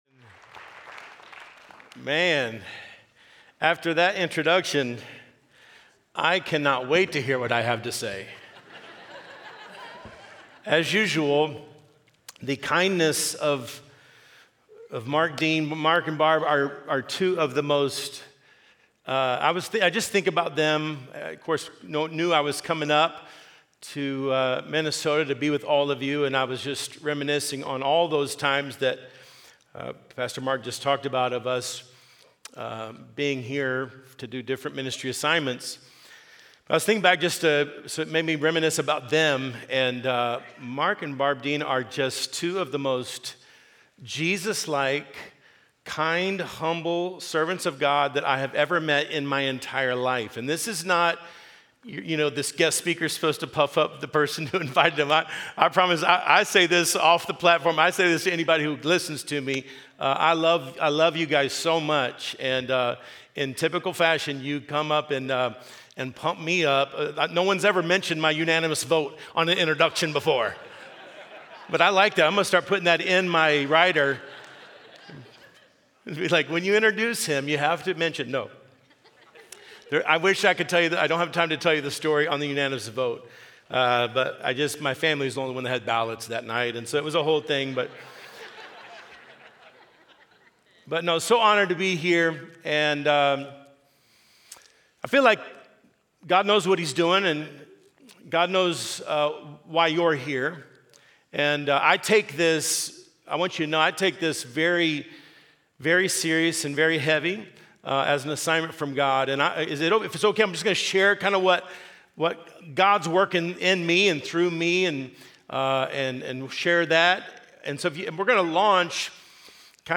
District Council 2026 – Leadership Session